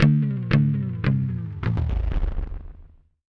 GUITARFX10-R.wav